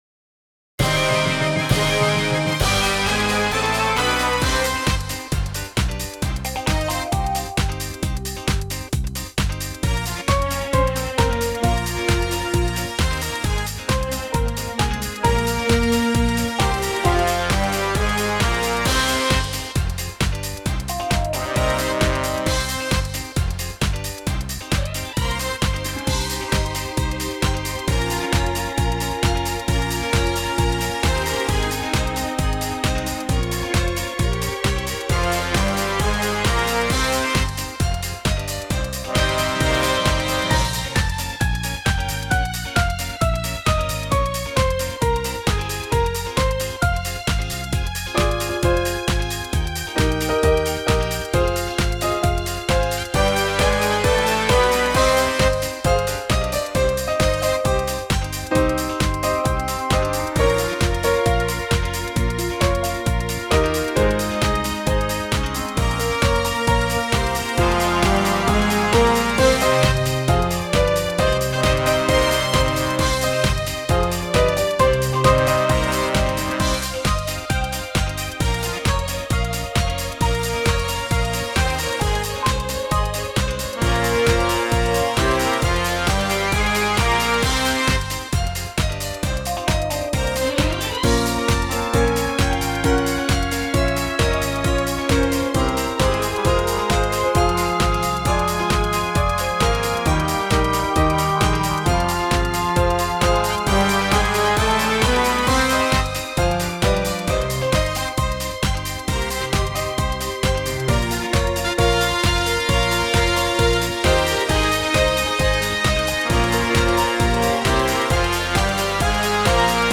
минус предлагается)